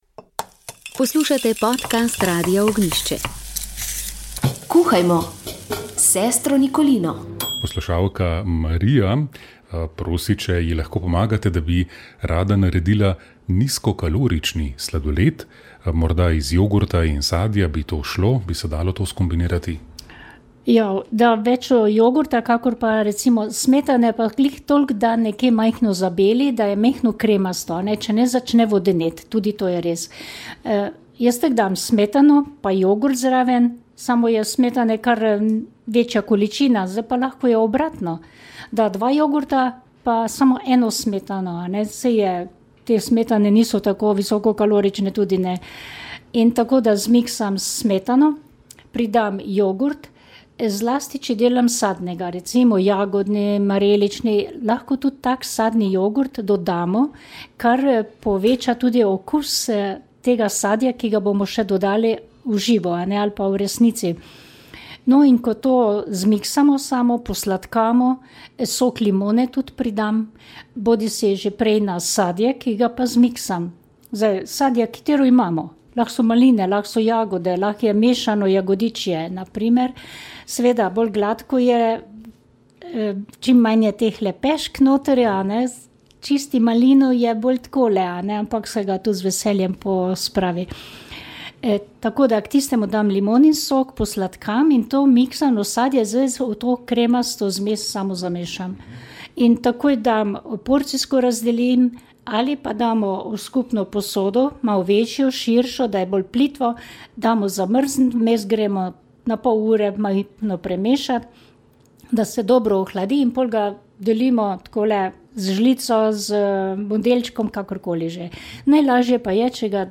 Poslušalka je prosila za recept za nizkokalorični sladoled iz jogurta in sadja.